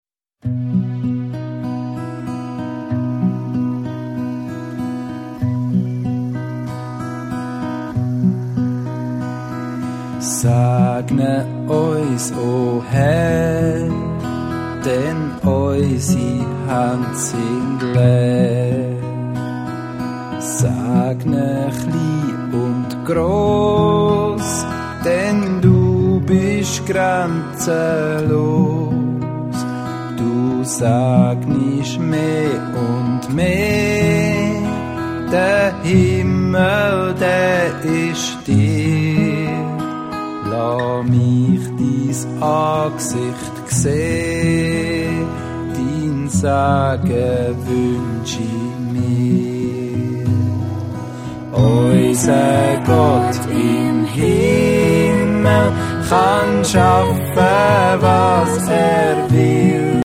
Dialektlieder zwischen Alltag und Anbetung.